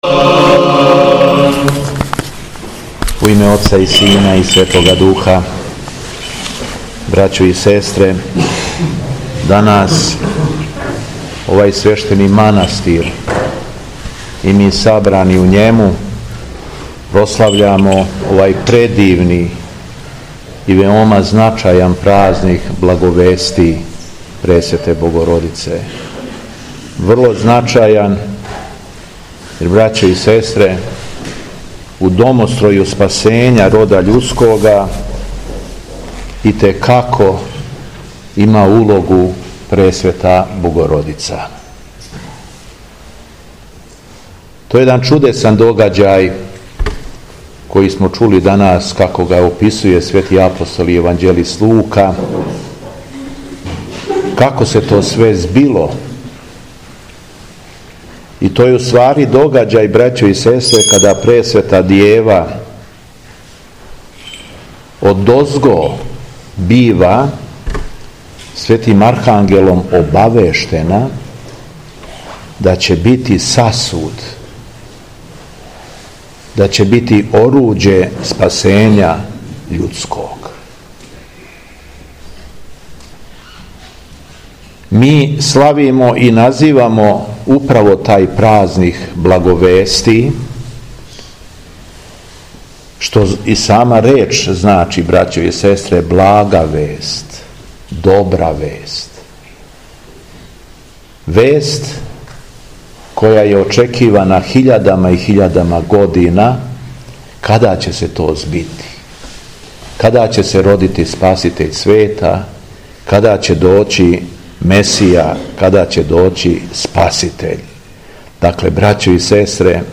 Беседа Његовог Преосвештенства Епископа шумадијског г. Јована
На празник Благовести Пресвете Богородице, Његово Преосвештенство Епископ шумадијски Господин Јован служио је Свету Архијерејску литургију у манаситру Дивостин.